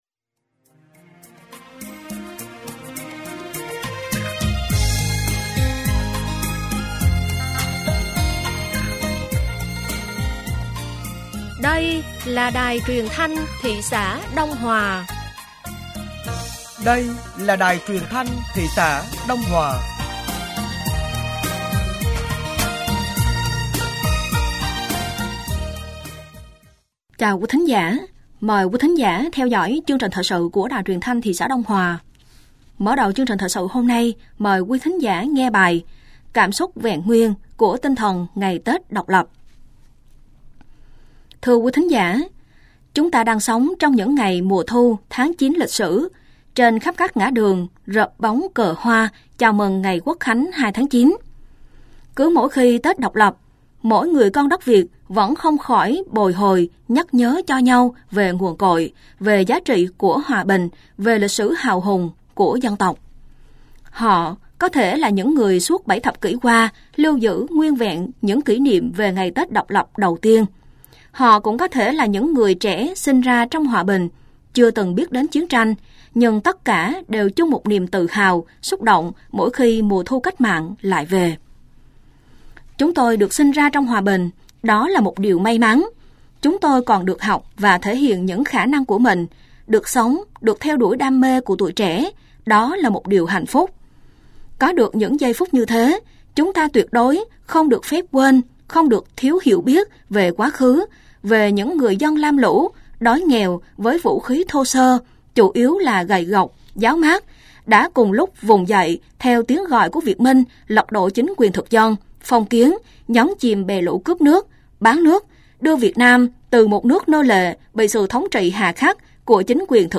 Thời sự tối ngày 03 và sáng ngày 04 tháng 9 năm 2024